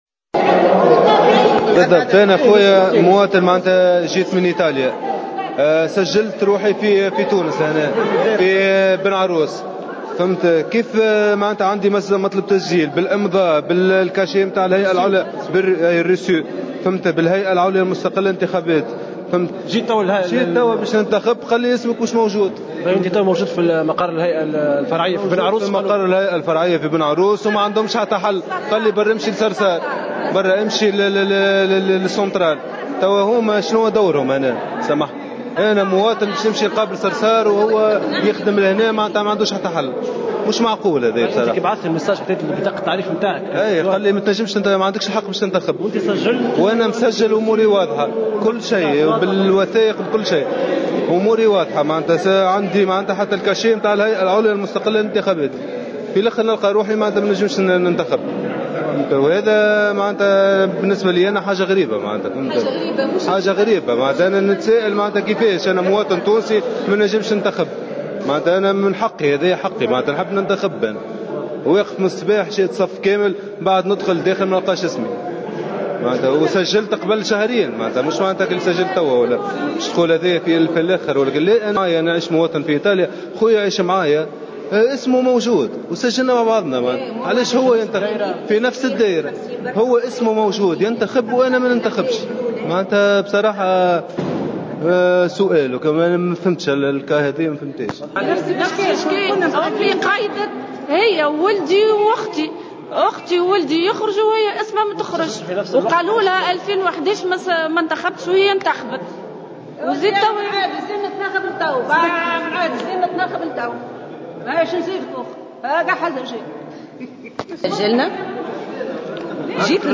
Au micro de Jawhara Fm, des électeurs ont exprimé leur déception et amertume de ne pouvoir accomplir leur de vote.